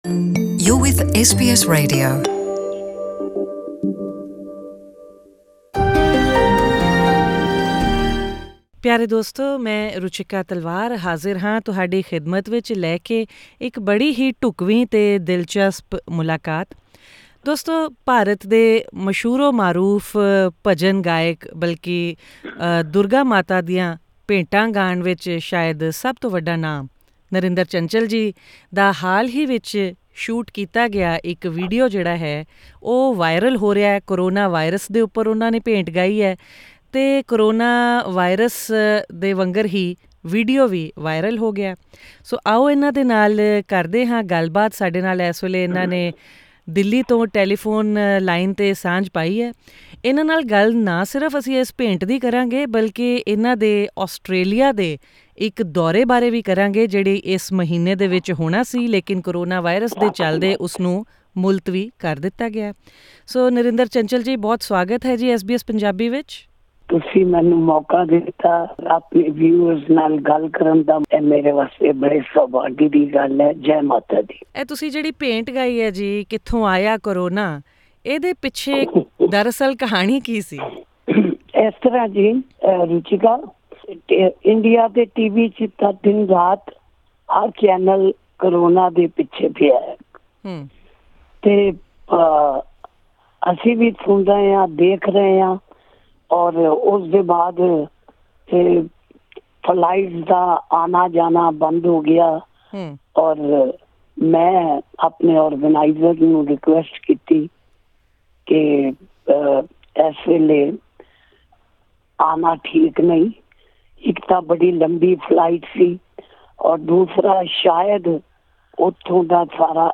SBS Punjabi spoke with him over the phone about what inspired him to not only sing the song but also pen the lyrics.